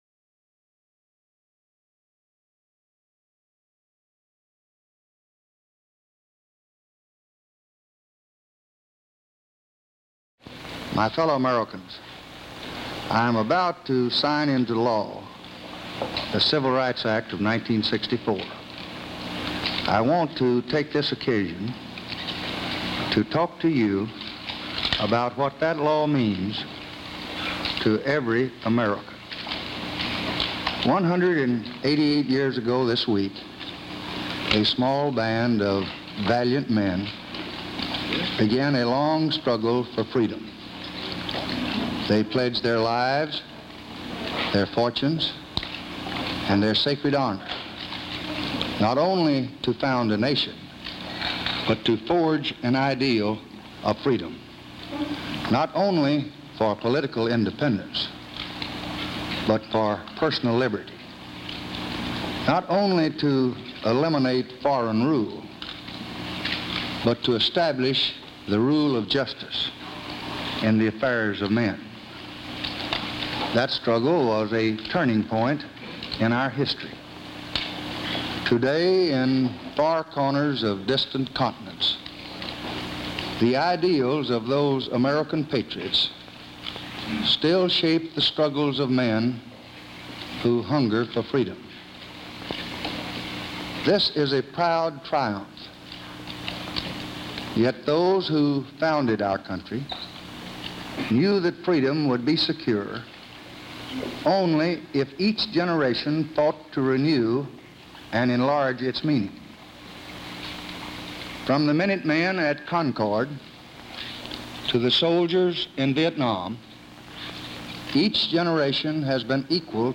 July 2, 1964: Remarks upon Signing the Civil Rights Bill